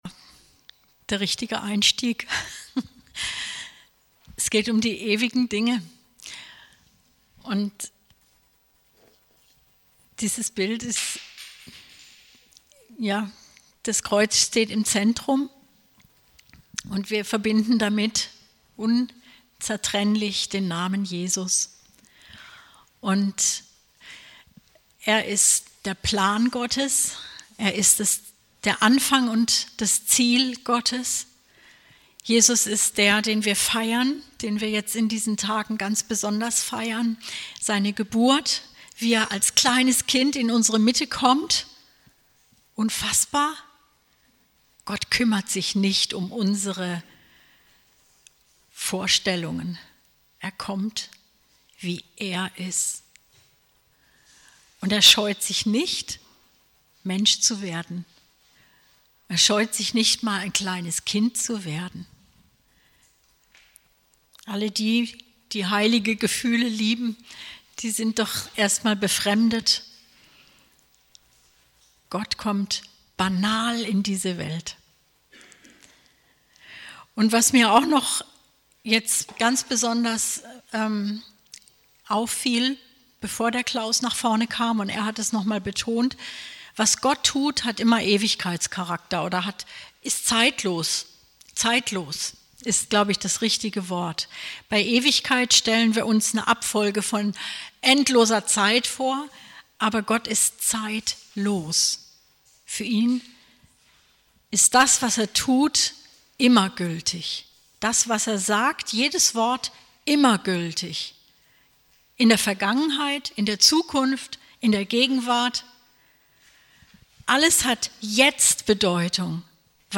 Predigt 23.12.2018: Was wir wirklich feiern sollten